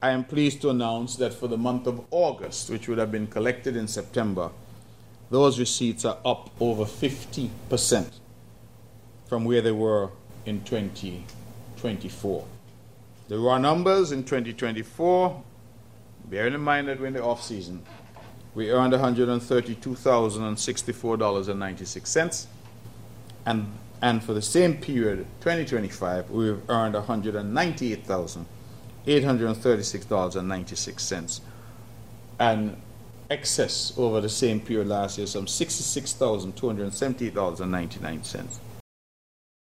During his monthly conference he asserted “This government is investing in a more significant way in the NTA because we think if the NTA does its job right, Nevis will prosper.”